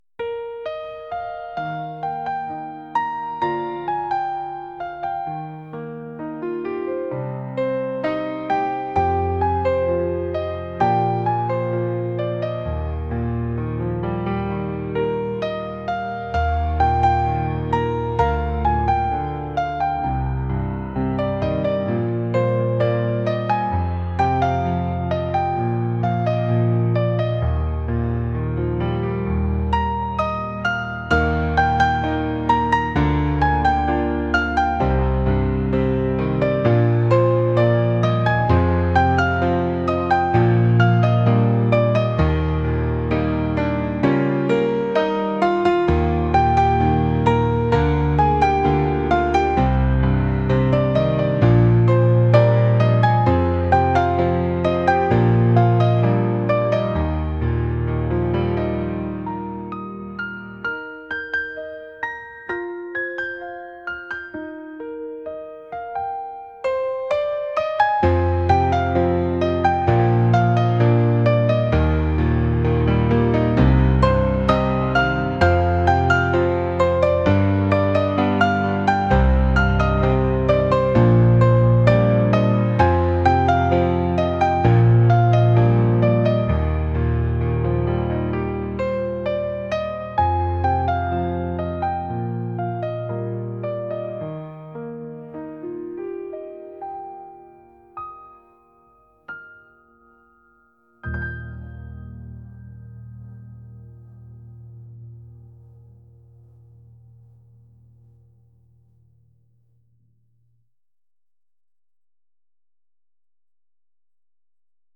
pop | acoustic | ambient